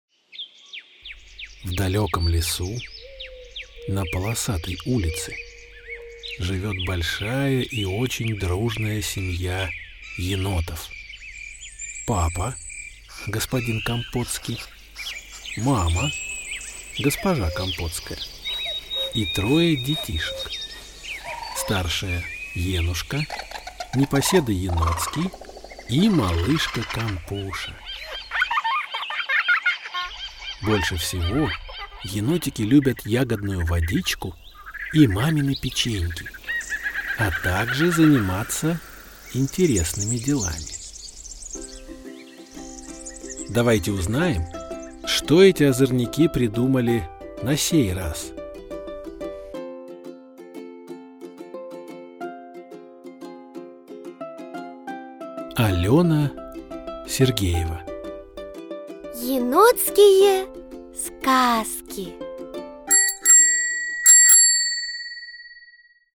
Аудиокнига Енотские сказки | Библиотека аудиокниг
Прослушать и бесплатно скачать фрагмент аудиокниги